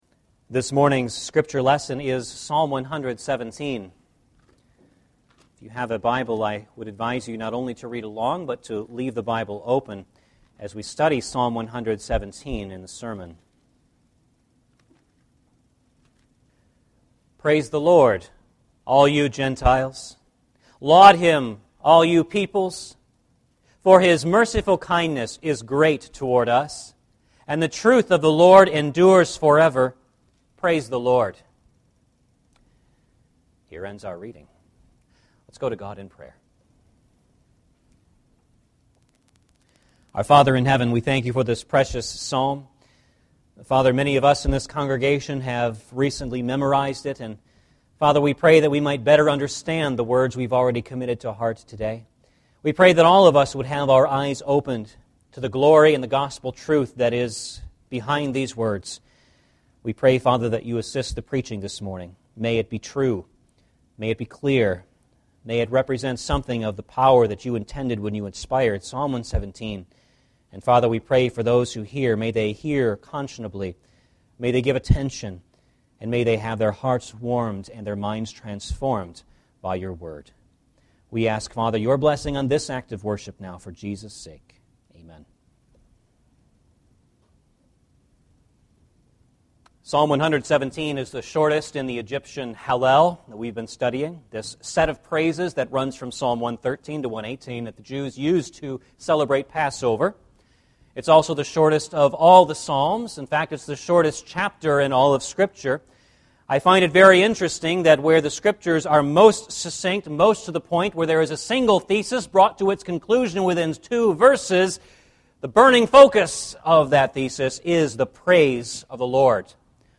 Psalms of Passover Passage: Psalm 117 Service Type: Sunday Morning Service « Church History #40